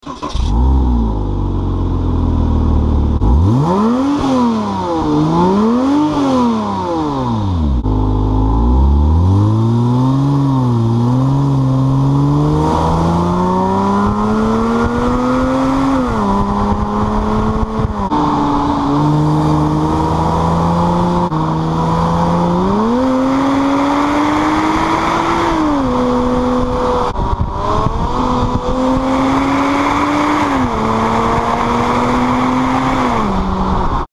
Porsche-Boxster-Spyder.mp3